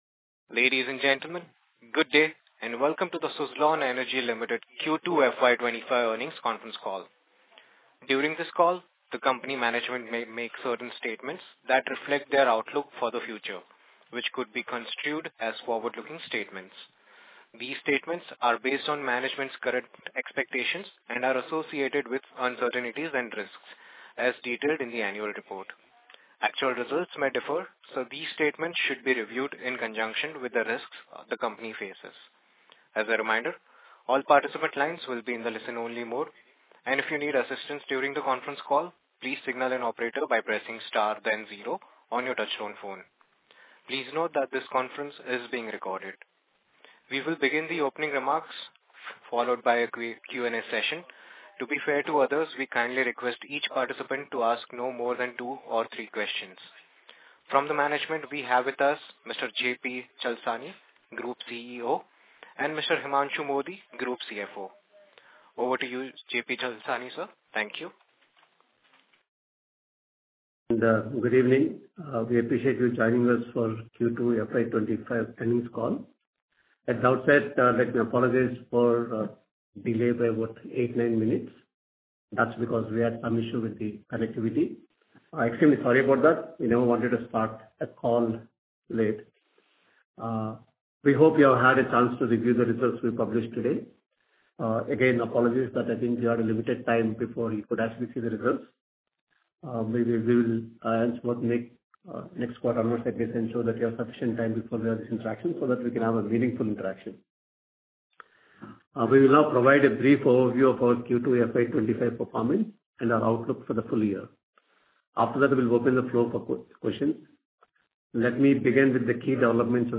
The Q2 FY25 earnings call paints a positive picture for Suzlon Energy.